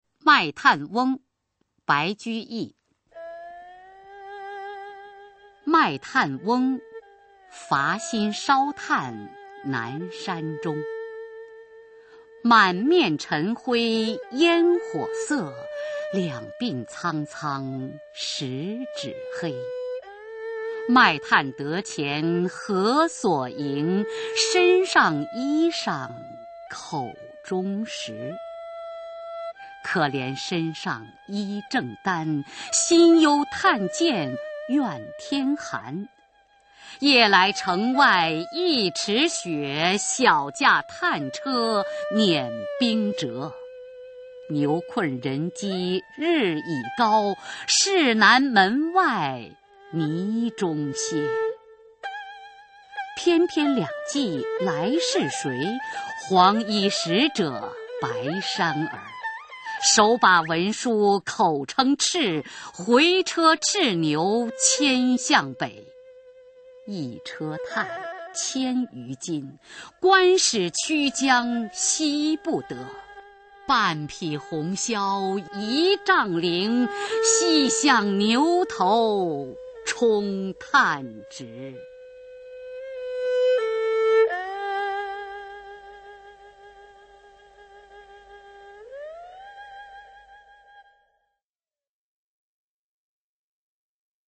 [隋唐诗词诵读]白居易-卖炭翁（女） 唐诗诵读